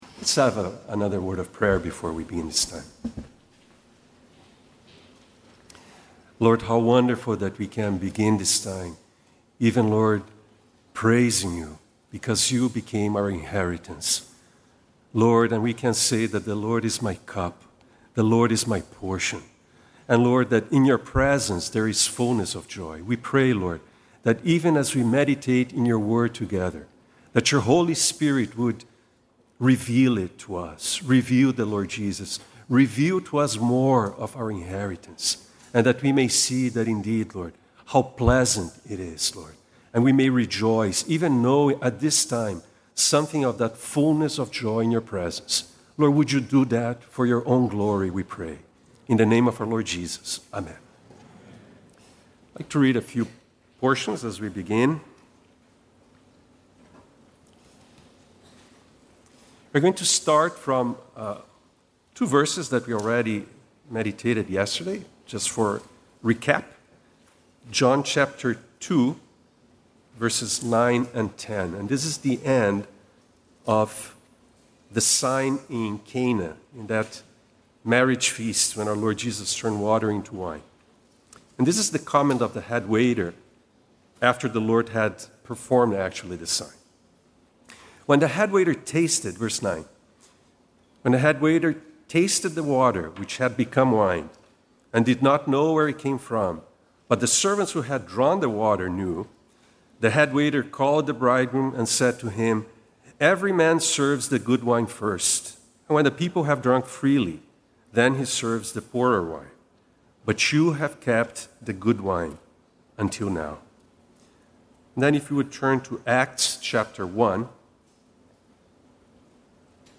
Harvey Cedars Conference